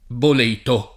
boleto [bol$to o
bol%to] s. m. (bot.) — regolare l’-e- aperta in voce di formaz. dòtta, dal lat. boletus; ma attestata fin dall’800, e oggi preval. in Tosc. e fuori, una pn. chiusa, dovuta all’attraz. delle molte altre voci in -eto appunto con -e- chiusa (non certo all’-e- lunga dell’etimo lat., che qui non ha avuto continuaz. popolare) — casi simili quelli di amuleto, aneto, criceto, sterleto e, in parte, quello di Orvieto